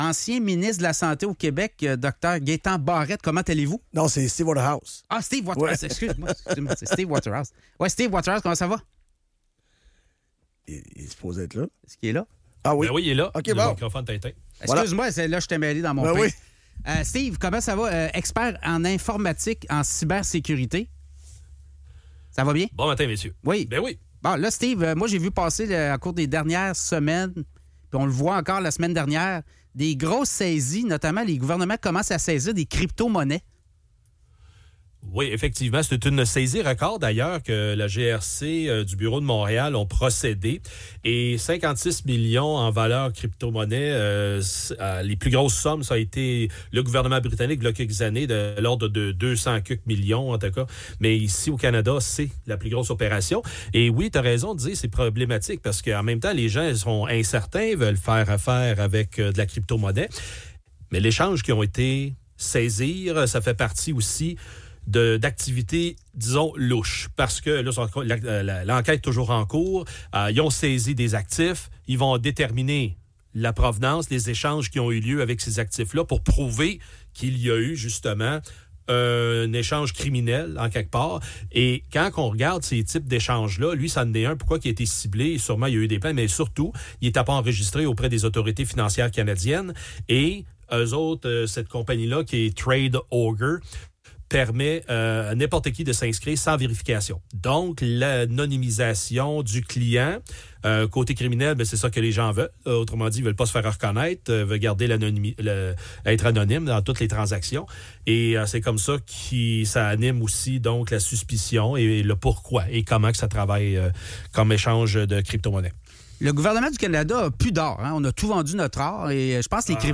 Enrtevue